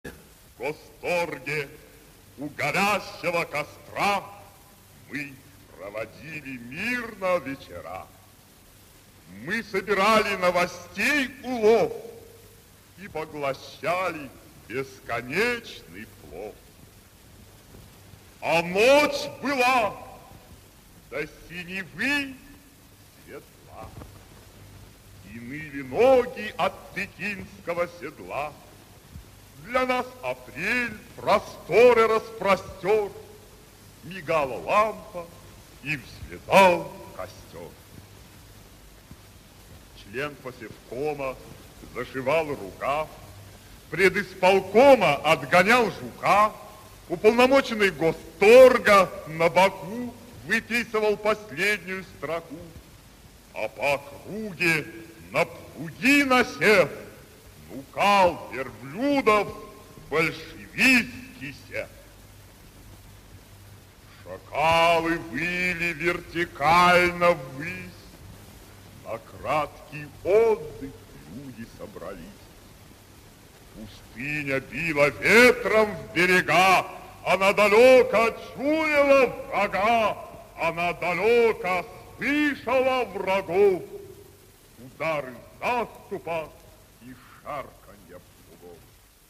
1. «Владимир Луговской – Большевикам пустыни и весны (отрывок) (читает автор)» /